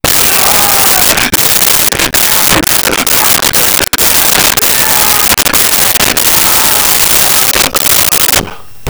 Fighting Yelling Male 02
Fighting Yelling Male 02.wav